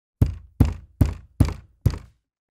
Play, download and share pounding on wall original sound button!!!!
pounding-on-wall.mp3